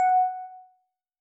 添加三个简单乐器采样包并加载（之后用于替换部分音效）